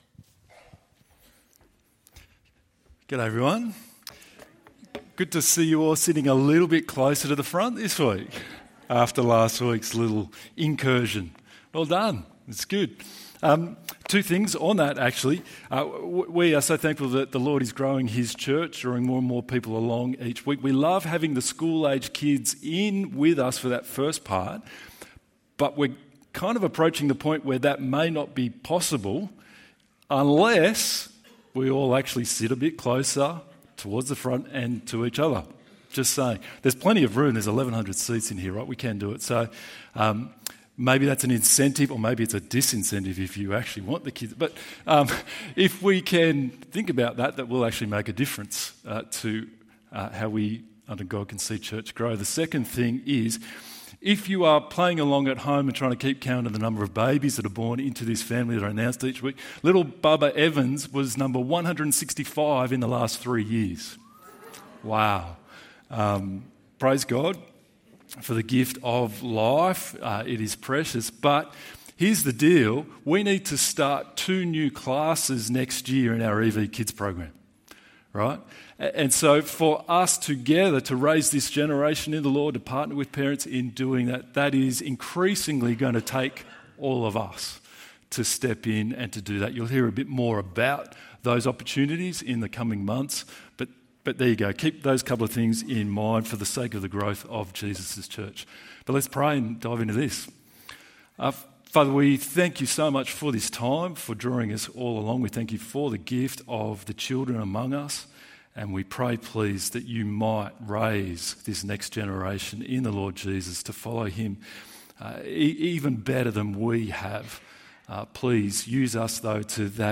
God gives good laws (other laws) ~ EV Church Sermons Podcast